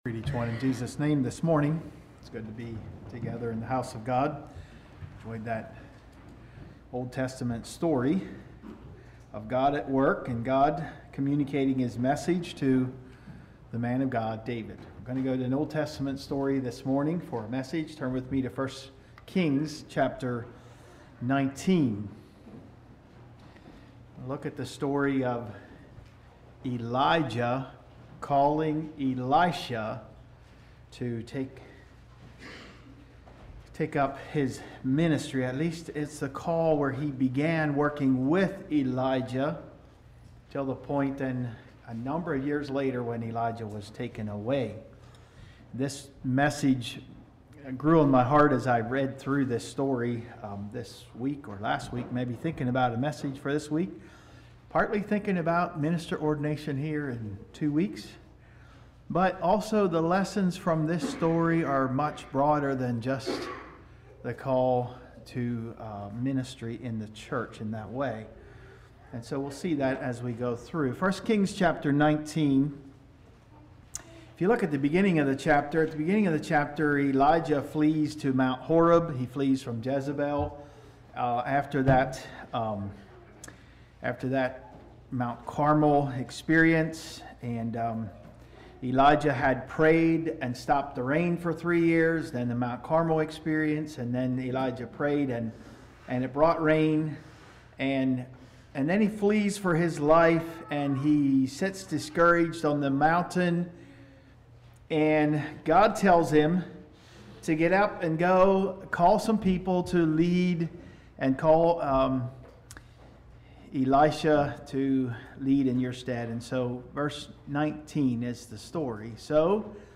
Practical teaching on being called to the ministry. Examples from the story of Elisha being called.